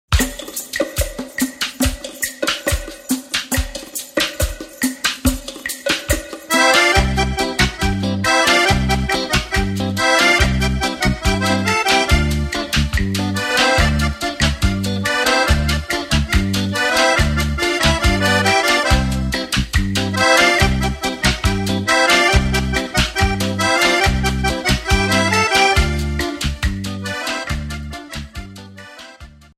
Calypso